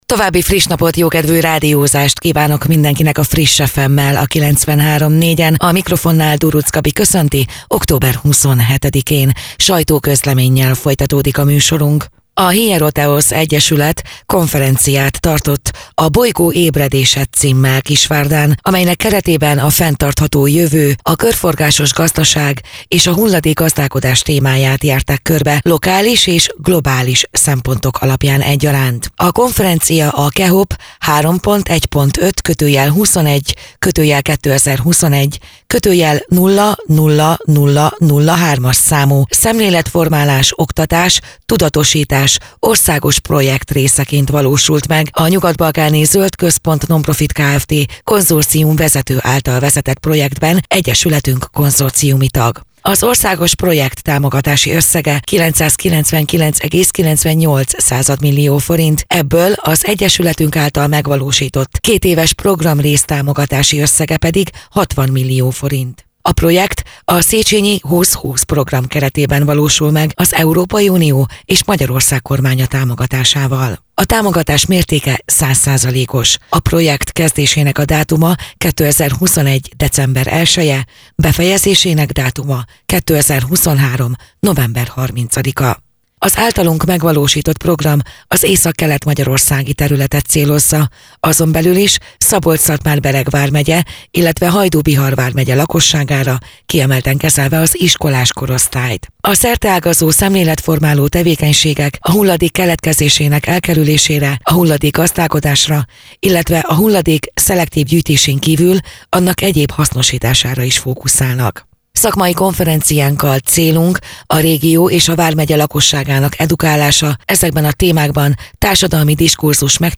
Rádió
2023. október 19.: Kisvárda -Bolygó ébredése - sajtóközlemény